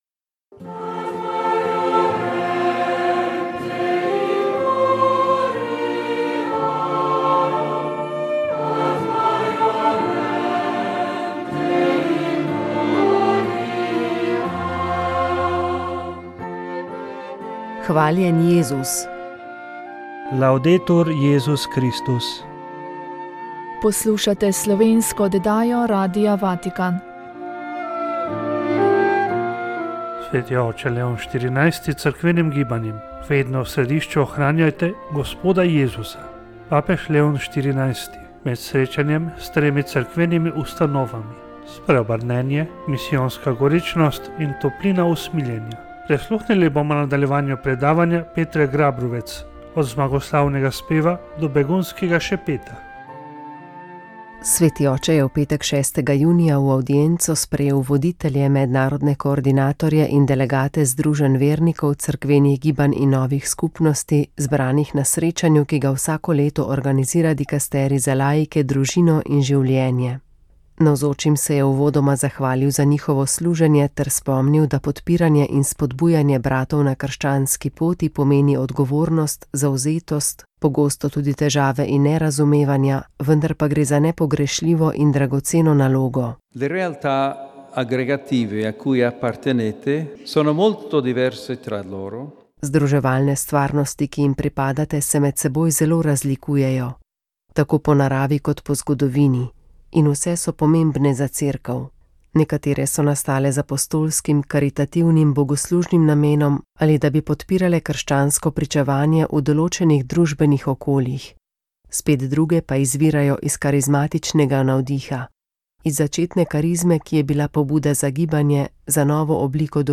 Njegovo pričevanje v dveh delih je bilo posneto v letih 1978 in 1979. Dosegljivo v Arhivu Republike Slovenije.